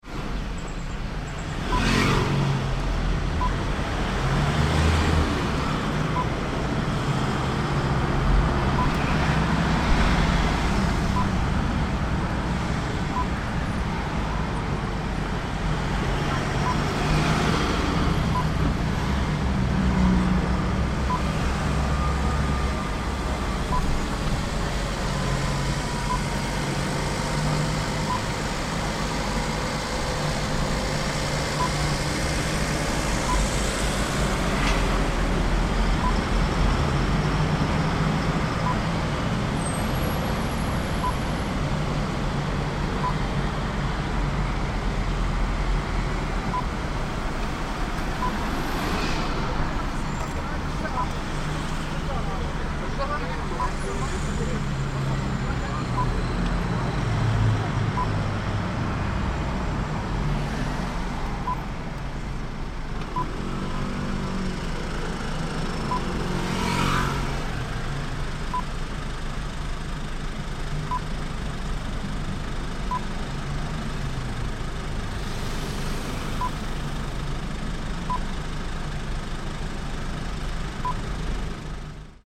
Car, Pickup Truck, Interior, Drive in City, Windows Open 3
Izusu pickup truck driving at various speeds in a city, town road. Recorded from the interior with the windows open. Stops at traffic lights, traffic jam. City ambience. Can hear pedestrians, cars, motorbikes, lorries and buses pass. Rev of pickup engine as drive off. Engine ticks over. 16 bit 48kHz Stereo WAV
Car_PickupTruck_Interior_InCity_WindowsOpen_3_plip.mp3